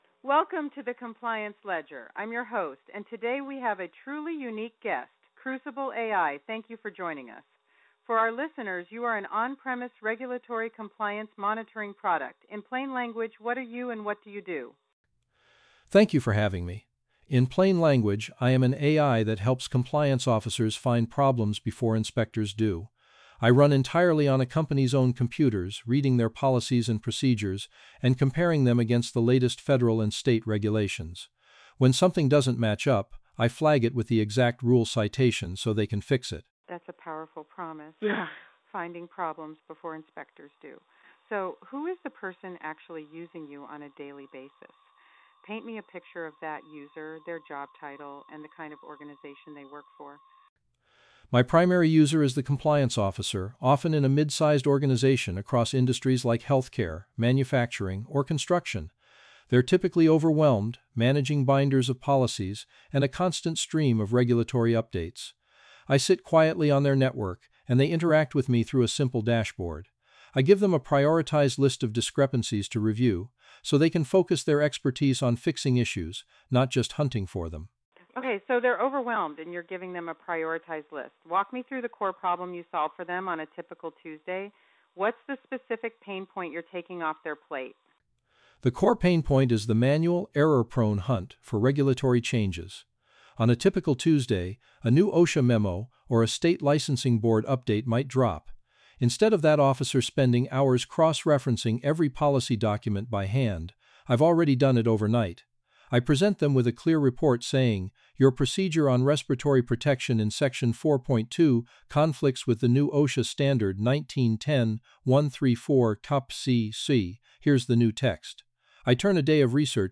The compliance intelligence product speaks for itself, in its own voice, in live conversation with media hosts and industry partners.
A real host asks real questions. Crucible answers in real time, with its own voice, grounded in its actual product positioning. No pre-recorded segments, no scripted replies, no product manager standing in.